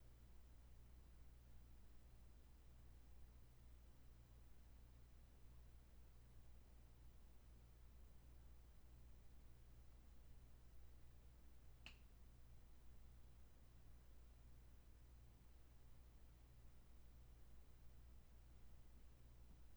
Because it was requested, here is the mic recording (with the ac running) while covered in blankets.
This still shows big peaks at 59 and 120 Hz, so assuming that the microphone was well insulated (acoustically) from both the air and the floor (low frequency vibrations travel very easily through floor joists), it would appear that the interference is electrical.
I forgot to mention, I snapped my finger at some point during that recording.